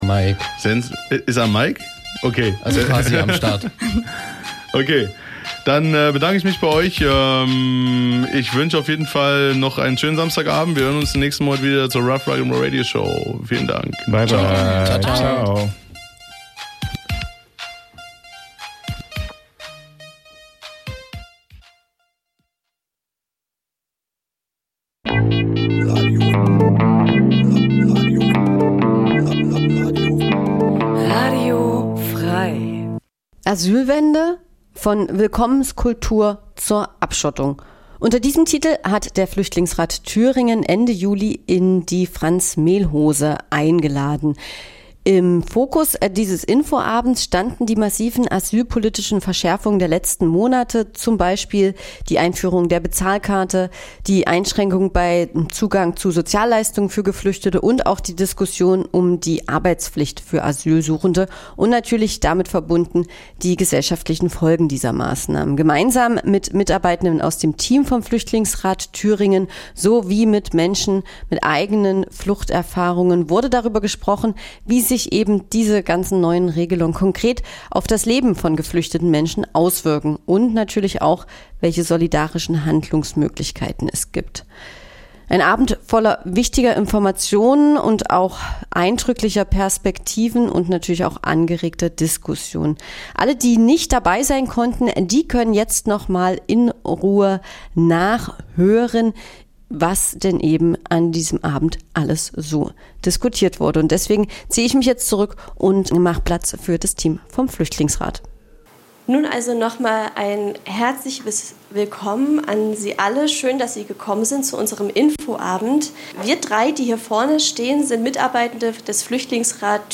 Mitschnitt der Veranstaltung �Asylwende? Von Willkommenskultur zu Abschottung� vom 29. Juli 2025 in der Franz Mehlhose